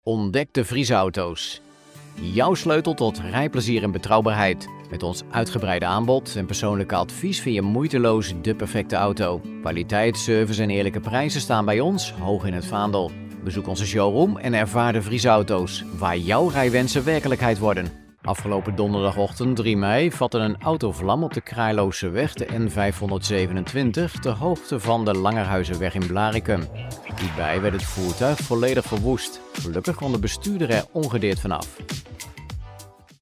moedertaal: nederlands engels mannenstem levering per e-mail mogelijk ervaring:gevorderde
klankleeftijd:klankleeftijd 25-40
Met mijn fijne, vertrouwde stem heb ik al vele complimenten ontvangen.